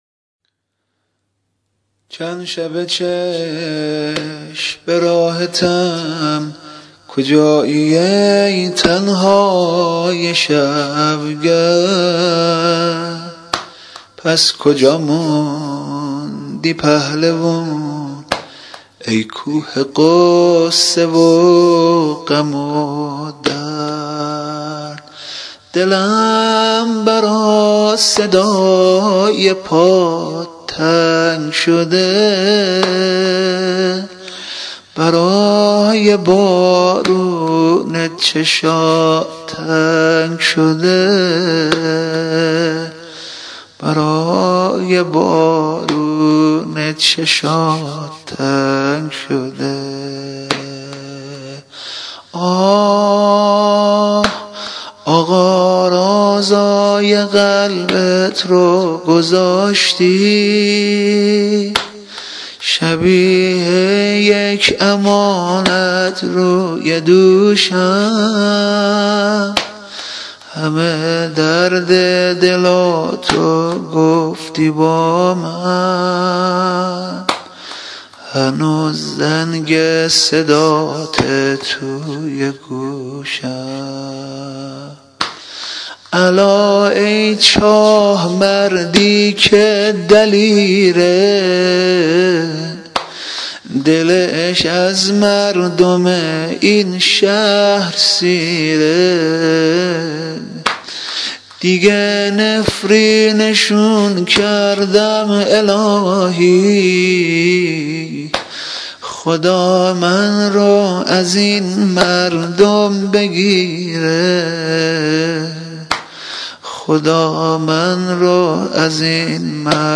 واحد ، زمزمه